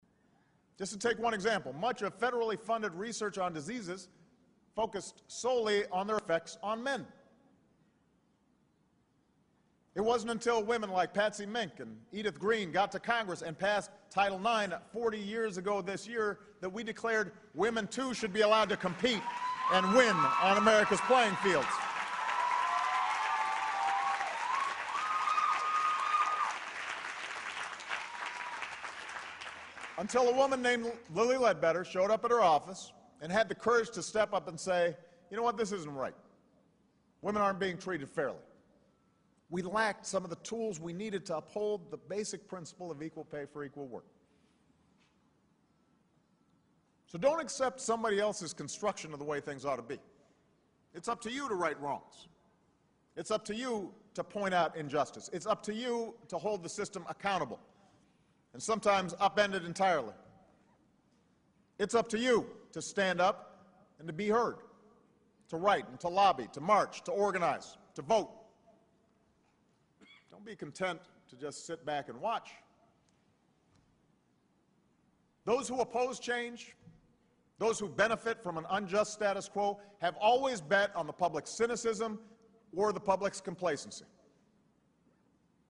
公众人物毕业演讲第393期:奥巴马2012年哥伦比亚大学毕业演讲(11) 听力文件下载—在线英语听力室